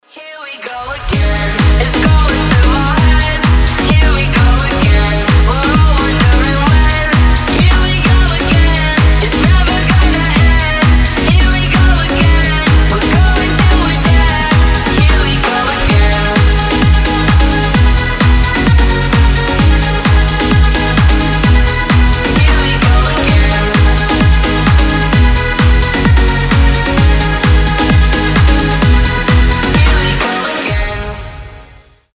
• Качество: 320, Stereo
EDM
евродэнс
piano house